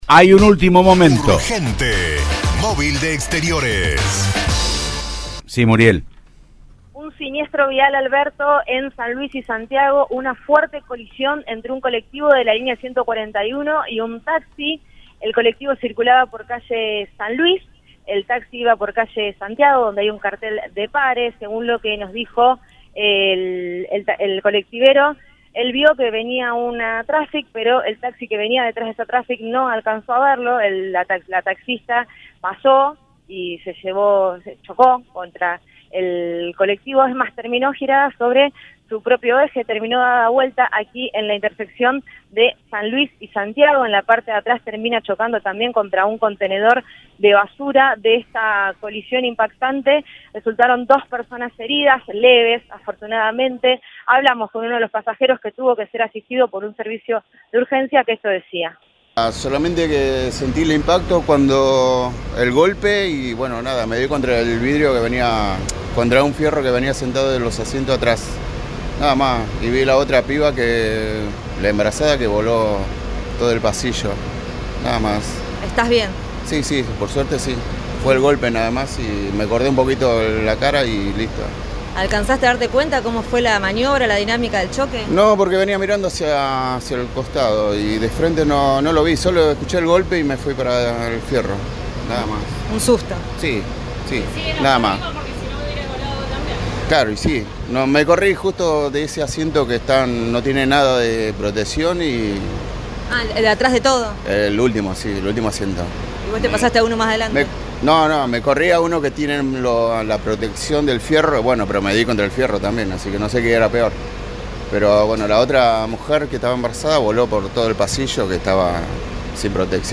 El otro herido habló con el móvil de Cadena 3 Rosario, en Siempre Juntos, y contó: “Sentí el impacto con el golpe.